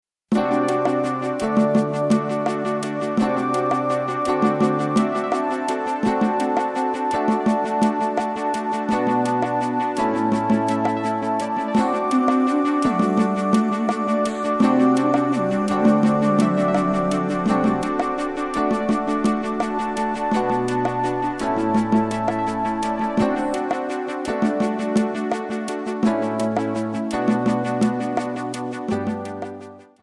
Backing track files: Pop (6706)
Buy With Backing Vocals.